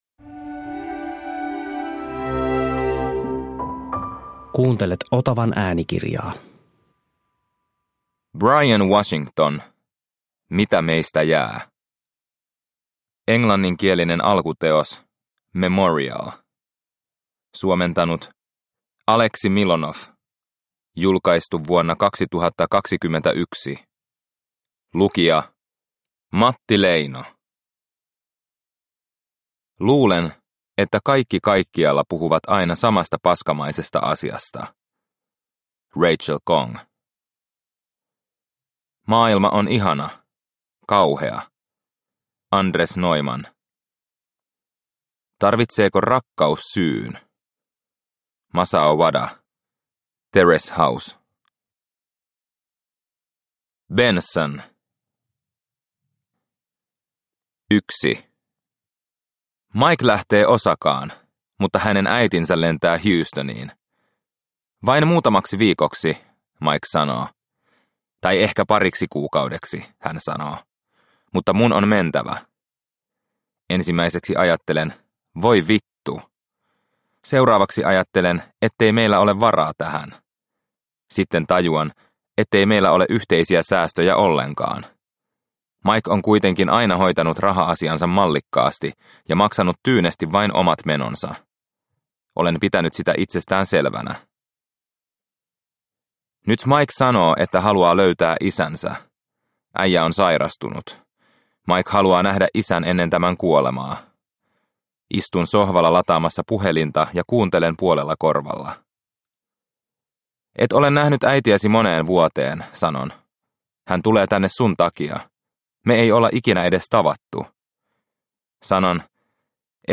Mitä meistä jää – Ljudbok – Laddas ner